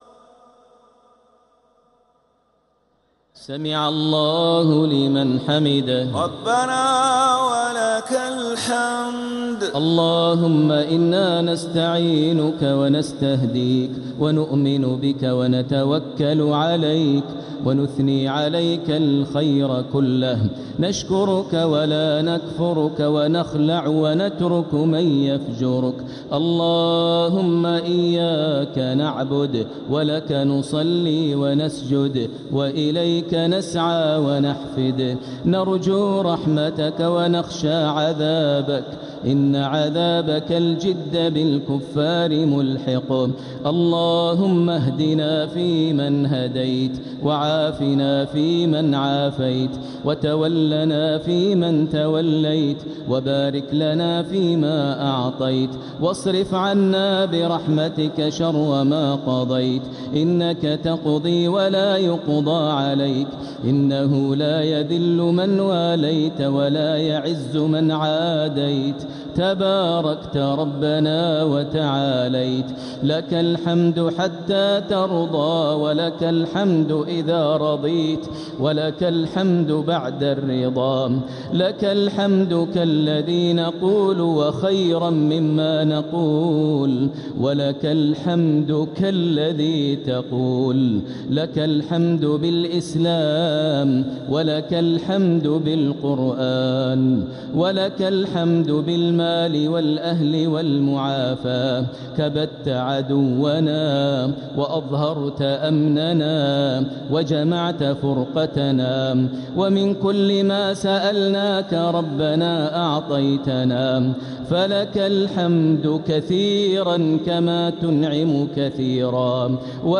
دعاء القنوت ليلة 22 رمضان 1447هـ | Dua 22nd night Ramadan 1447H > تراويح الحرم المكي عام 1447 🕋 > التراويح - تلاوات الحرمين